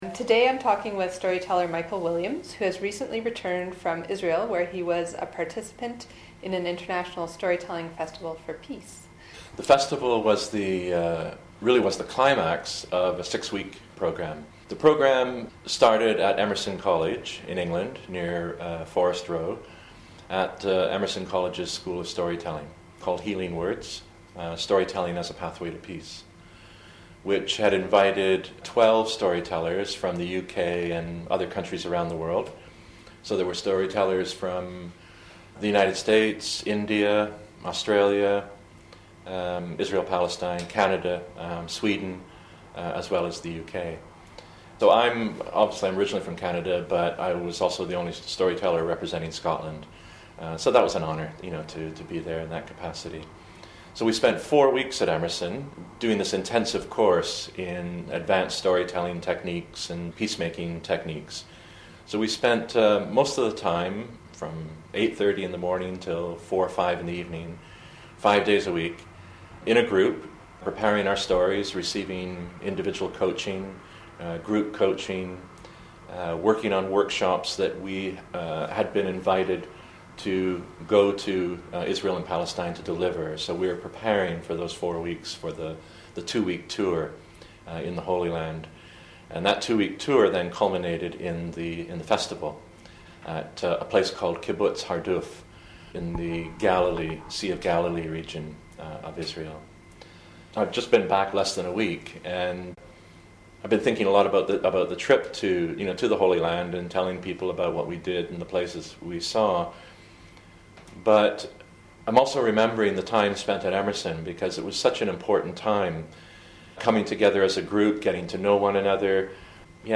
The tour was the culmination of a School of Storytelling program called Healing Words – Storytelling as a Pathway to Peace. In this interview